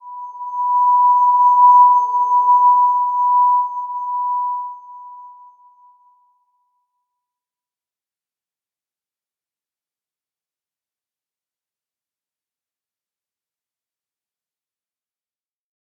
Slow-Distant-Chime-B5-p.wav